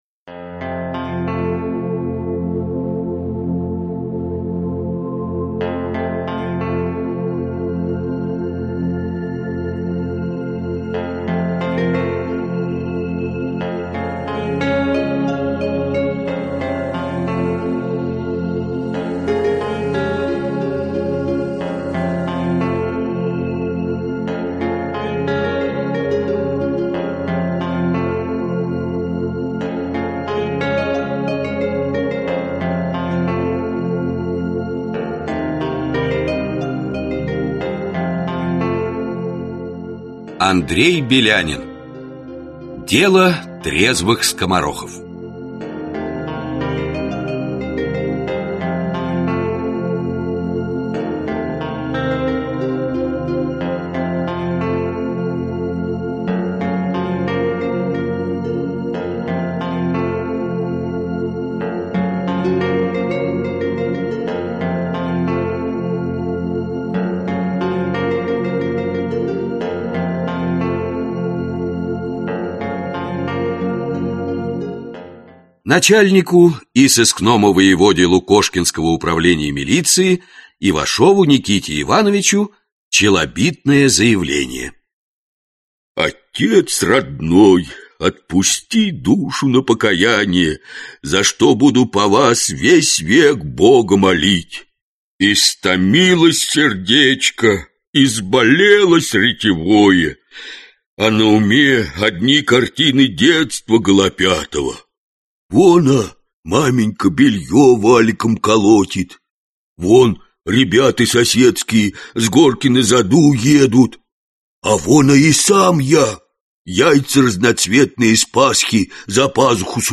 Аудиокнига Дело трезвых скоморохов | Библиотека аудиокниг